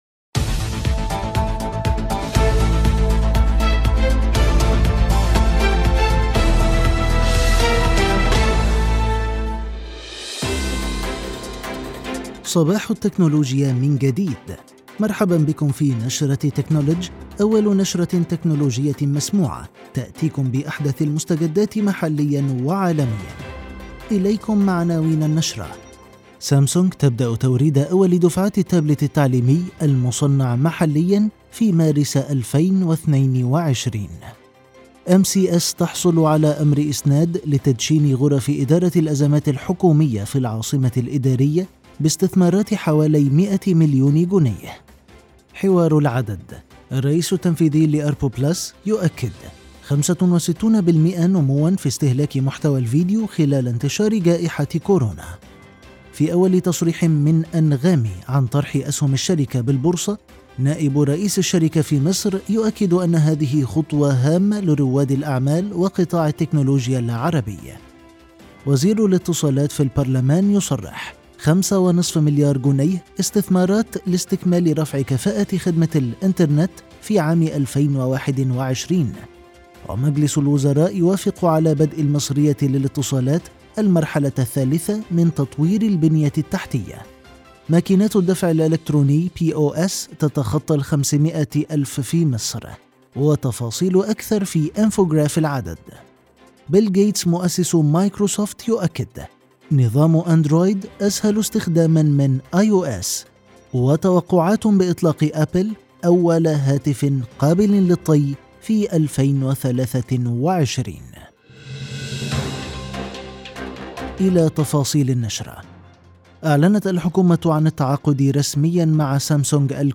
نشرة «تكنولدج» المسموعة.. العدد السادس